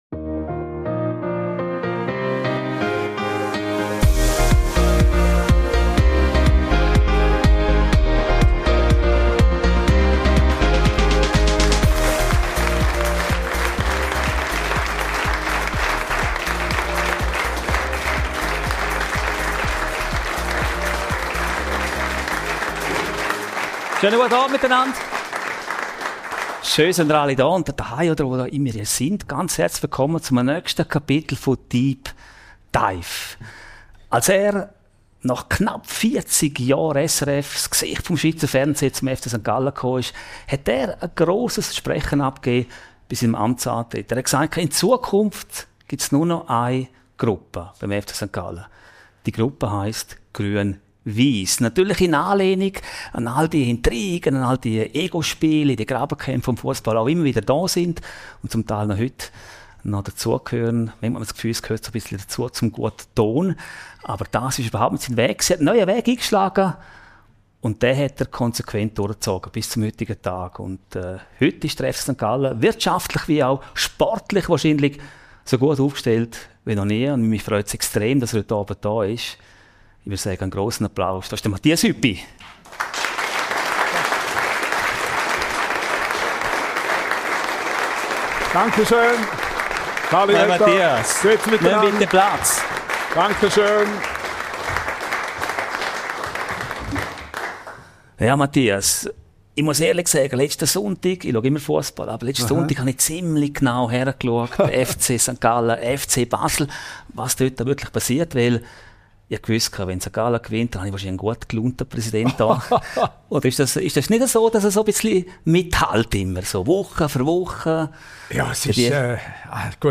Ein Gespräch über Verantwortung, öffentliche Wahrnehmung und Führung in einem emotionalen Umfeld.
Die Episode wurde live im From Heaven in Bad Ragaz aufgezeichnet – vor Publikum und in einer Atmosphäre, die Raum für persönliche Geschichten und echte Reflexion schafft.